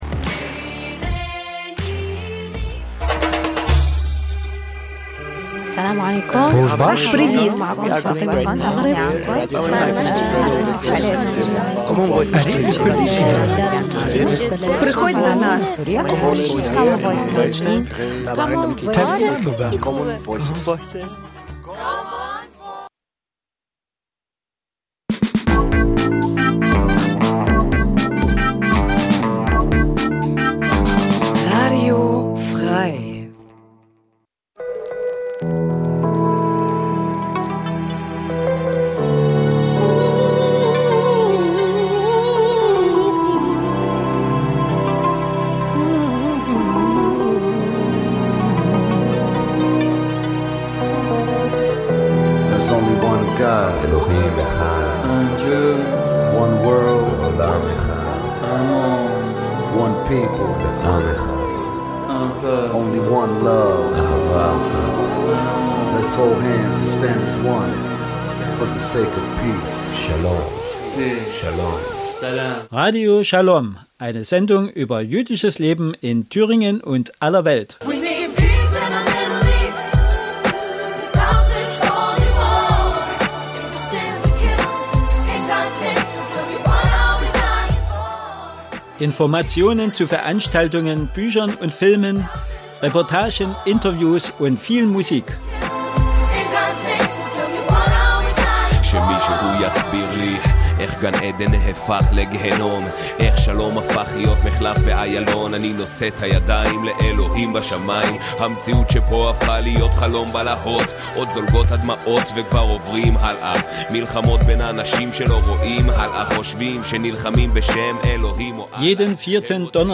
Radio Schalom berichtet �ber j�disches Leben in Deutschland, Diskussionen �ber Tradition und Religion, Juden in Israel und in der Diaspora sowie Musik aus Israel und anderen Teilen der Welt.